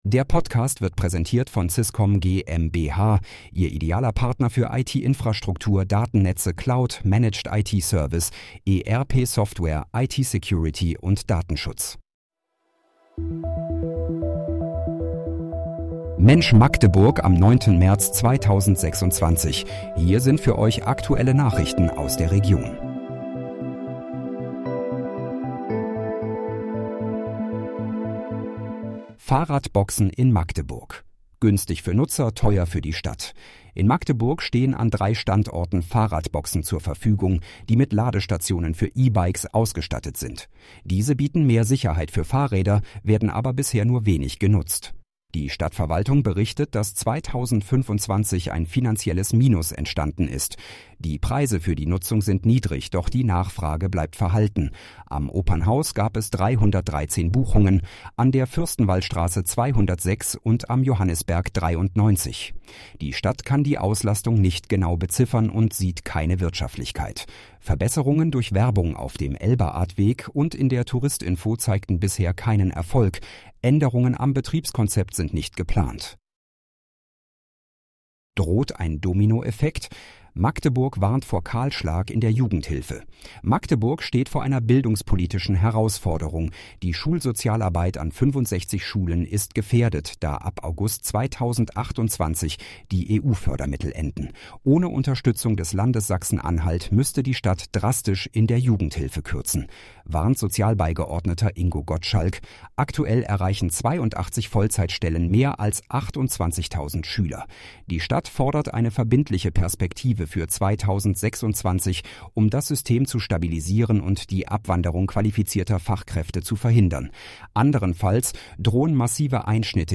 Mensch, Magdeburg: Aktuelle Nachrichten vom 09.03.2026, erstellt mit KI-Unterstützung